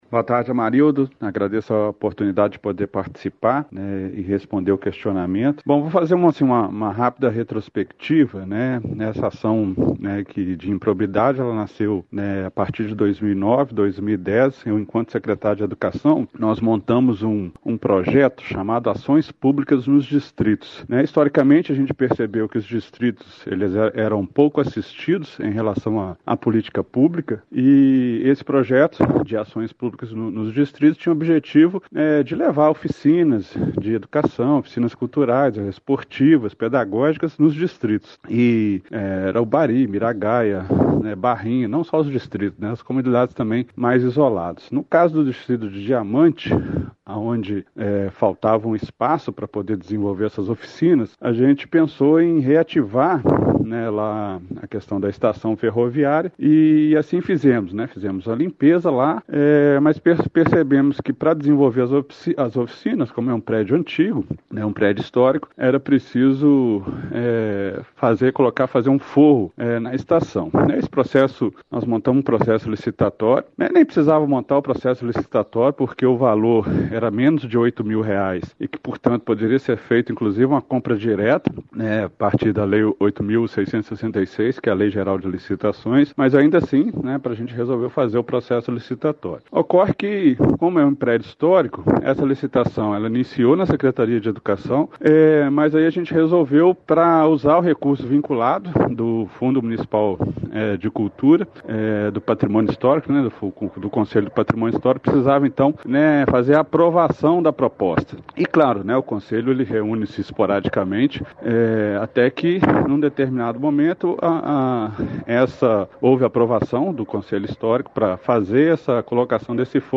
Procurado por nossa redação o secretário municipal de Educação respondeu por áudio via aplicativo de mensagens. Falou sobre a denúncia de improbidade, destacou que seu processo não enquadra na Lei de Ficha Limpa municipal e questionado se poderia ser um ato político, comentou.
Secretário municipal de Educação Samuel Gazolla Lima